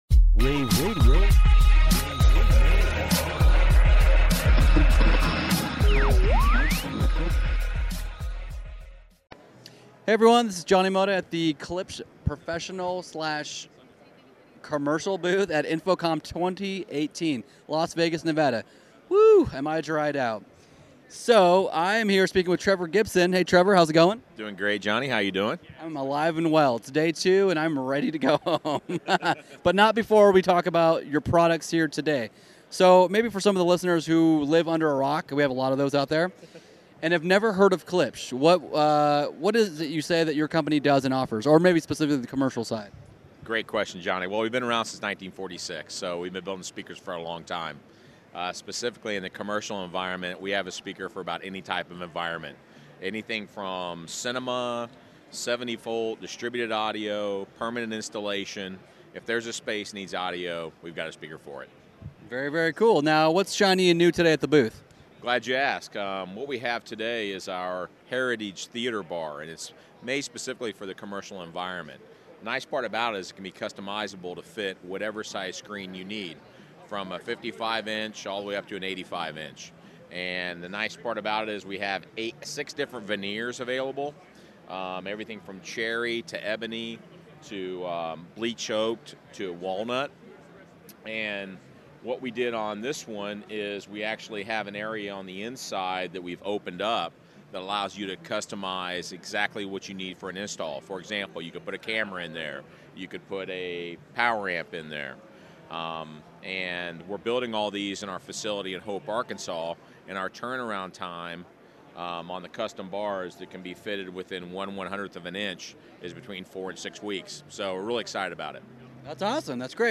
June 9, 2018 - InfoComm, InfoComm Radio, Radio, rAVe [PUBS], The Trade Show Minute,